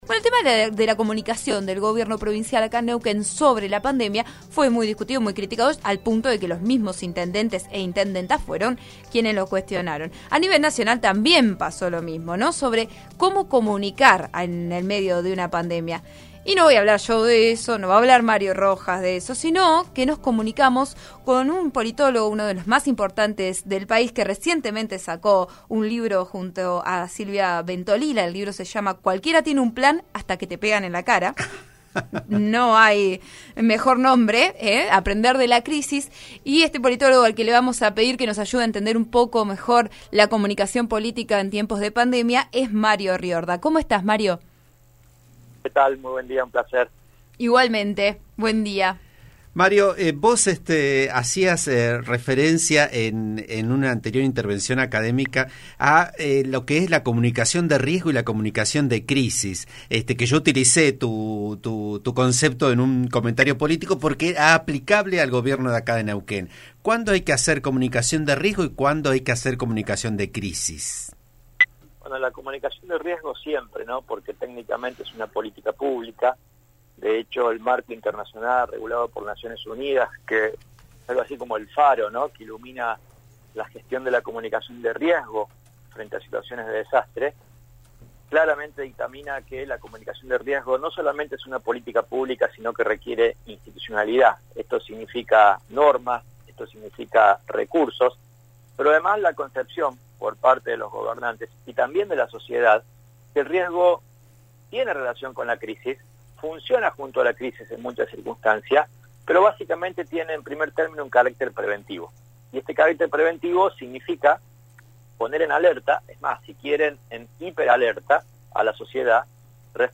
RN Radio: Entrevista
El especialista analizó la comunicación política en épocas de coronavirus. Habló en el programa Vos a Diario de RN Radio.